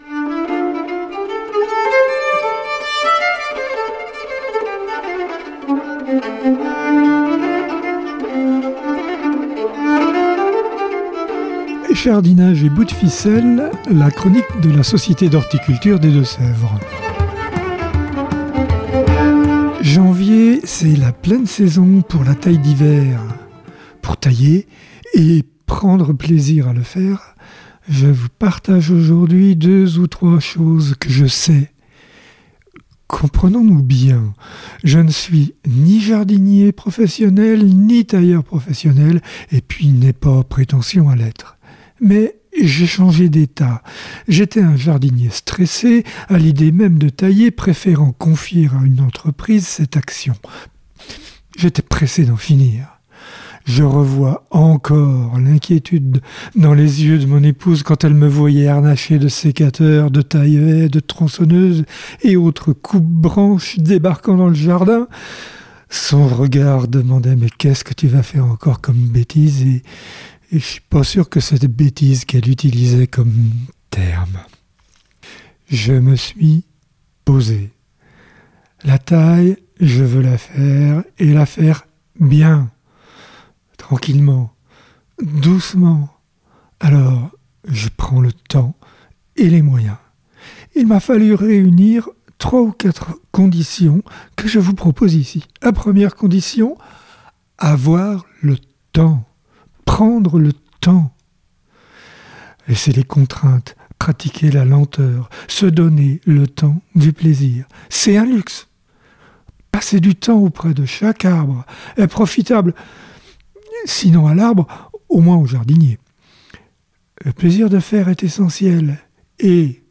(Ces chroniques sont diffusées chaque semaine sur les radios D4B et Pigouille Radio)